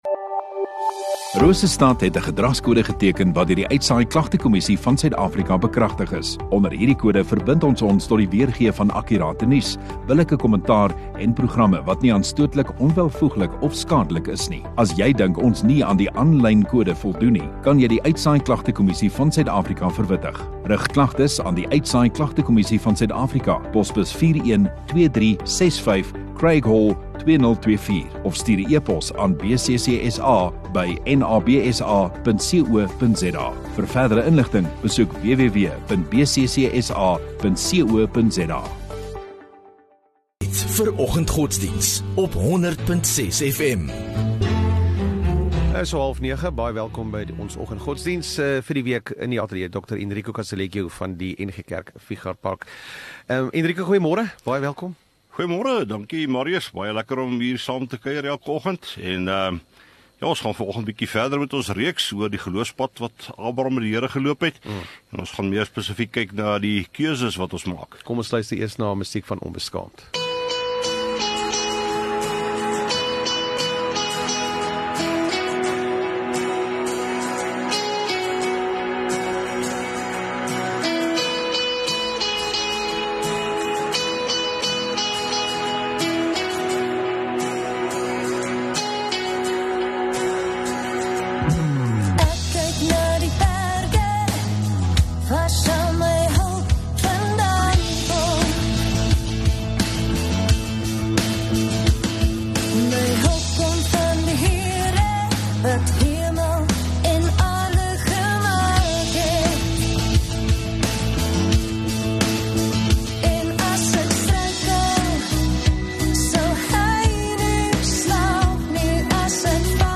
24 Jul Woensdag Oggenddiens